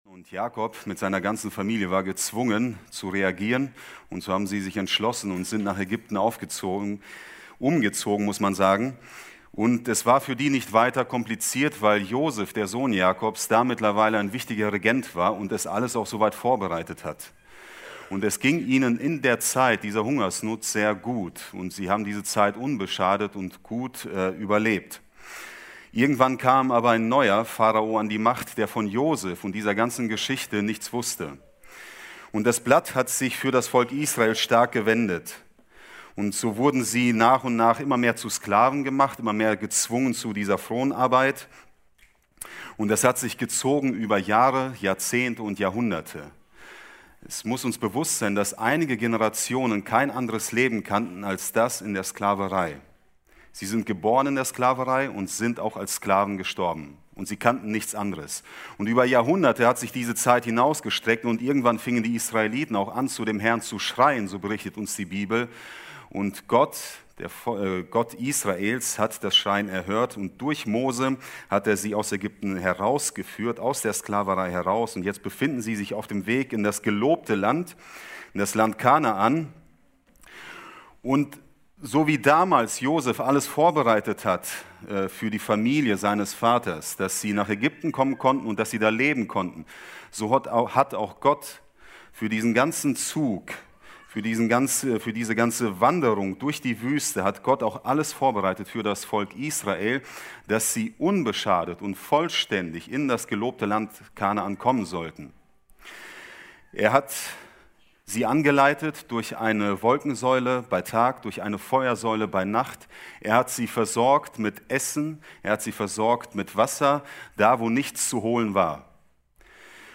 Predigten – Seite 12 – Bibelgemeinde Barntrup